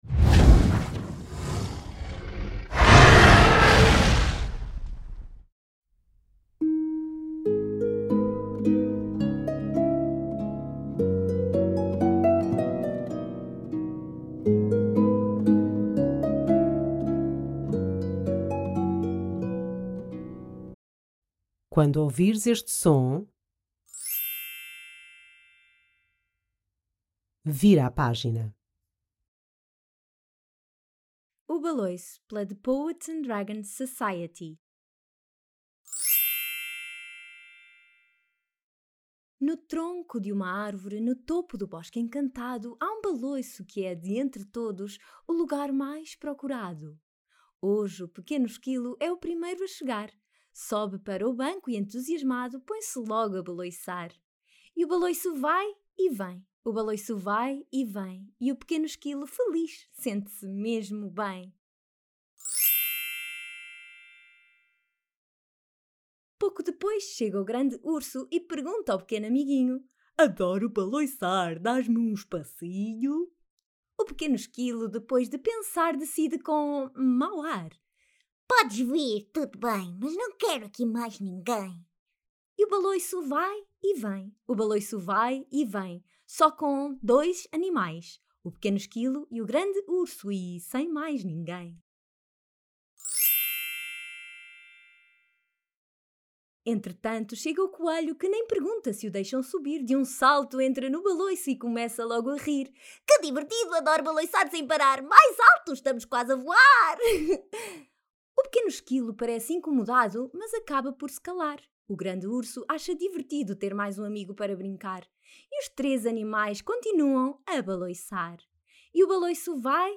Audiobook-O-Baloico.mp3